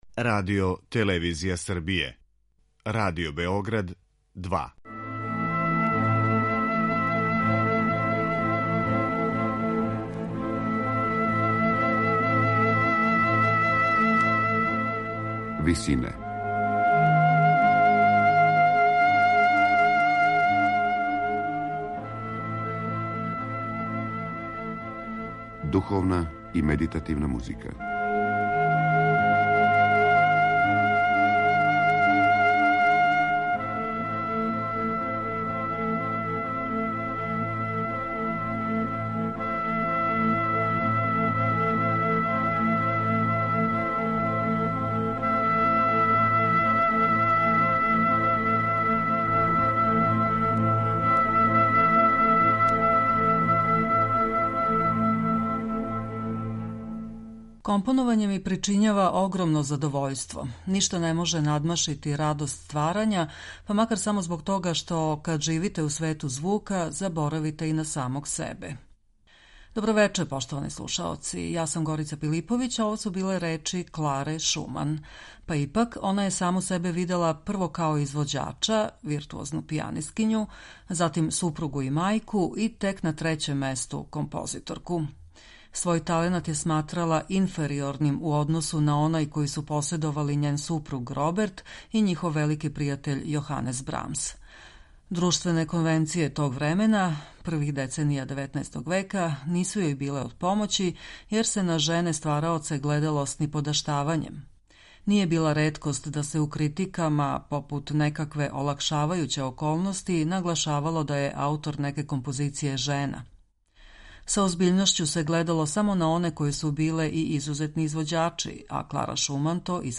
клавирске романсе